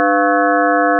sin_7.1ch.wav